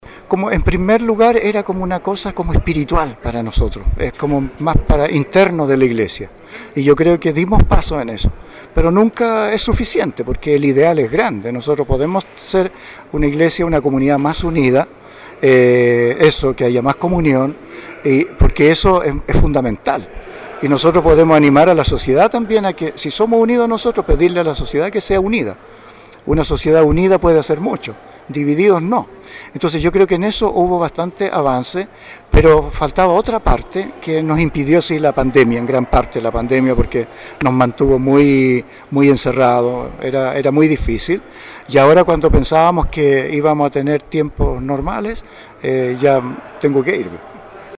Con la Iglesia Catedral San Mateo completa de fieles, en la tarde de ayer se realizó la Misa-Acción de Gracias por su servicio como pastor de la Diócesisde Monseñor Jorge Concha Cayuqueo.
El Pastor vivió el momento con mucha emoción, pero al mismo tiempo tranquilo por la labor desarrollada, y por la continuidad de su labor pastoral en otro territorio.
En un momento de su homilía, humildemente el pastor pidió perdón a los presentes por no haber podido avanzar más en algunos aspectos, o por si consideraron insuficiente lo desarrollado en lo pastoral, o si en su accionar ofendió a alguien.